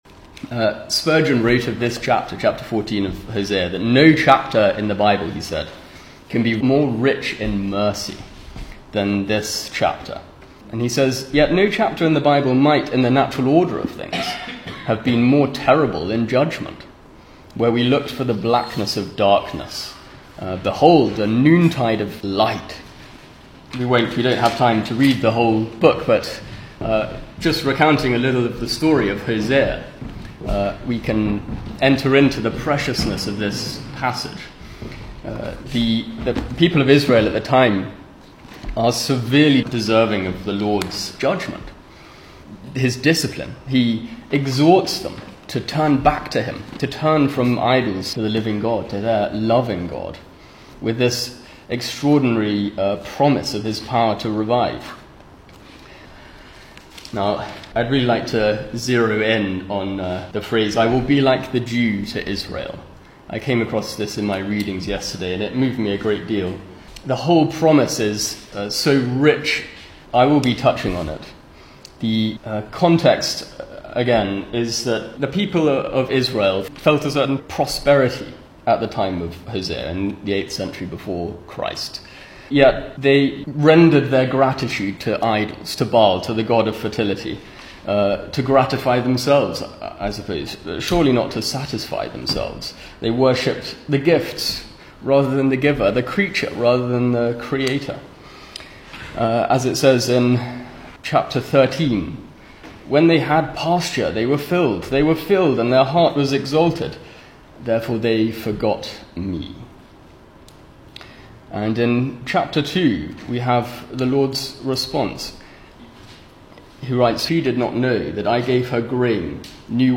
Service Type: Weekday Evening
Single Sermons